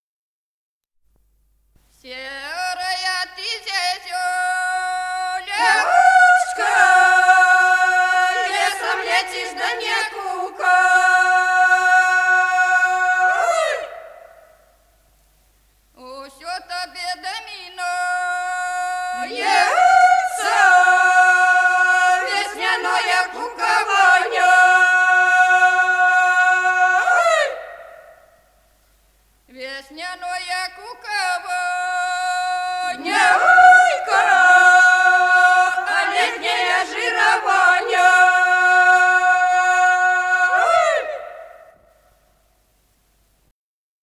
Народные песни Стародубского района «Серая ты зязюлечка», жнивная.
Записано в Москве, декабрь 1966 г., с. Курковичи.